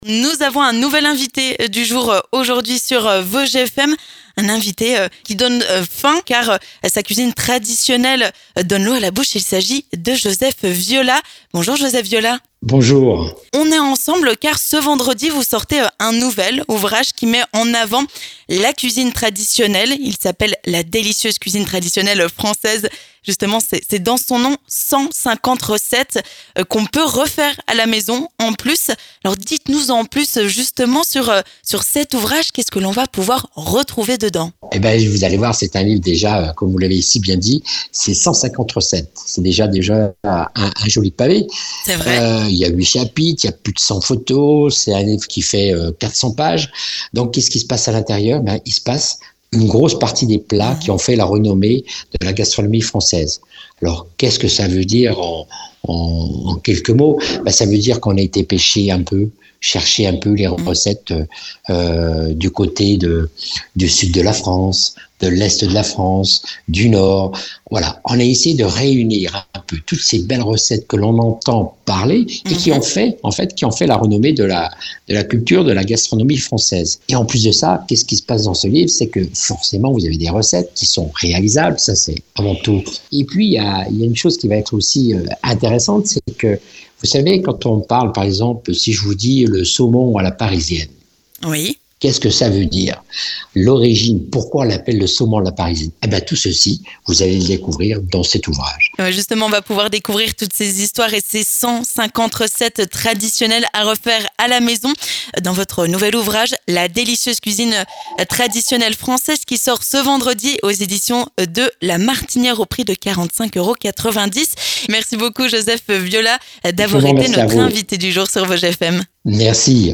notre invité du jour sur Vosges FM